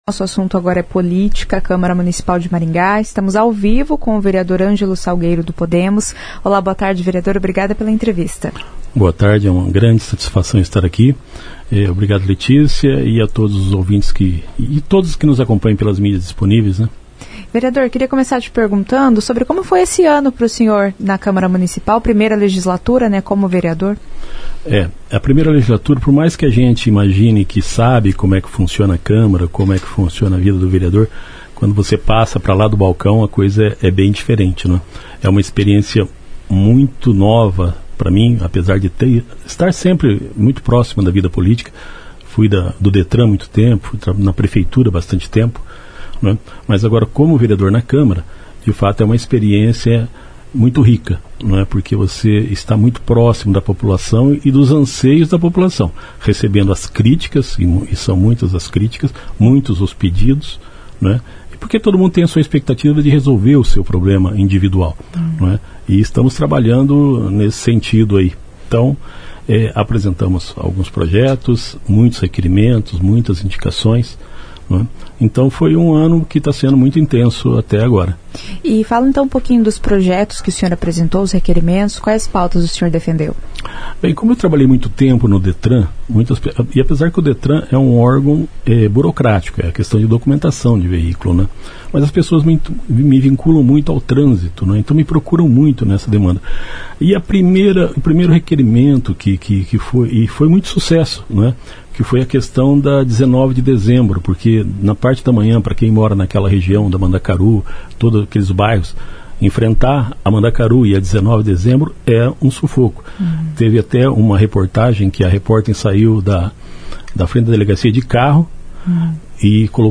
O vereador Angelo Salgueiro (Podemos) falou sobre os desafios de Maringá e as dificuldades enquanto vereador.